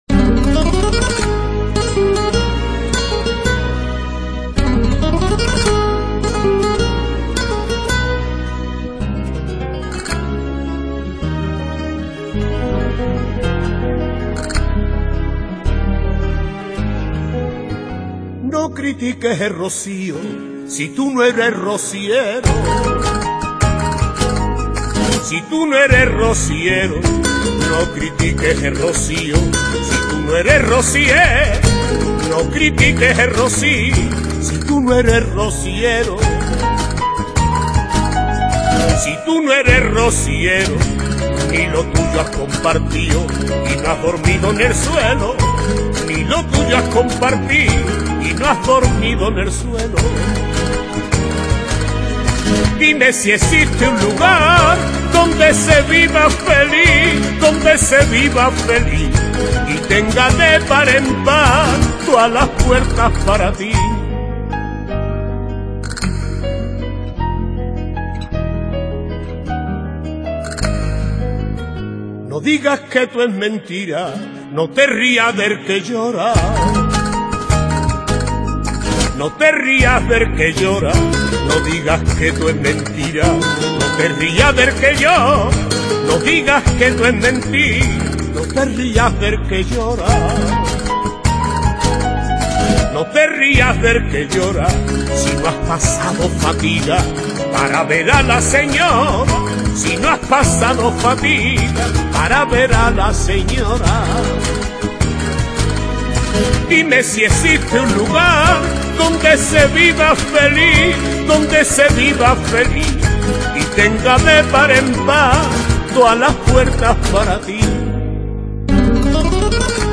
Temática: Rociera